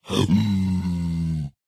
zpigangry4.ogg